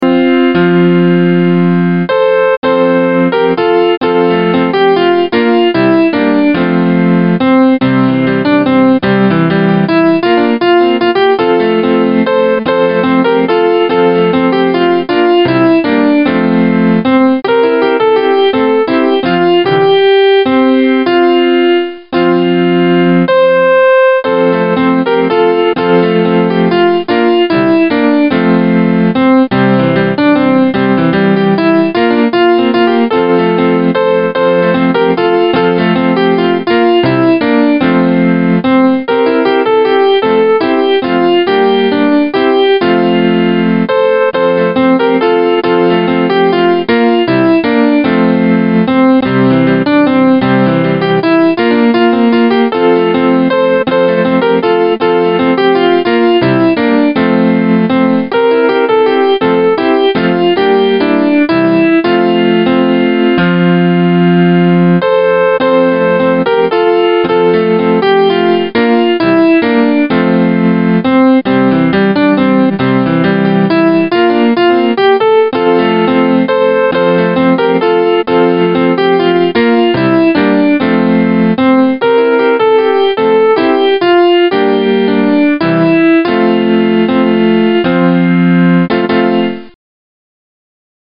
Voice used: Grand piano